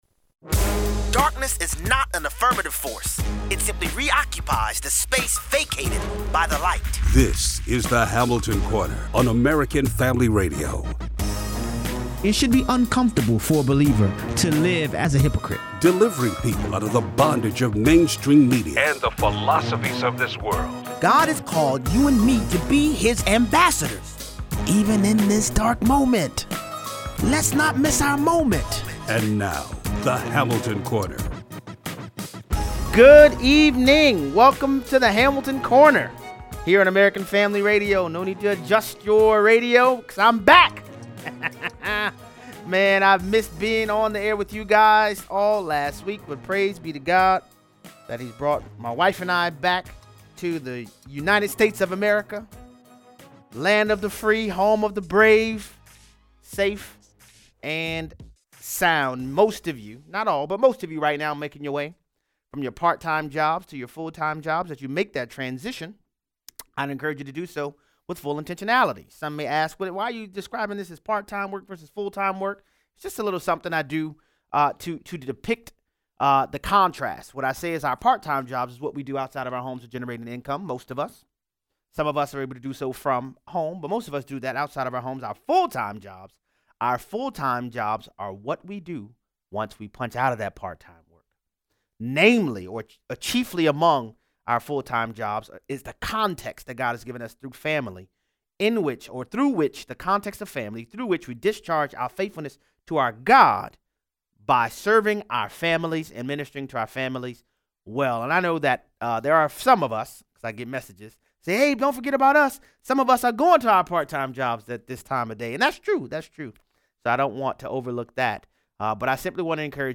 Iowa Caucuses begin tonight and Rush Limbaugh announces he has “advanced lung cancer.” Callers weigh in.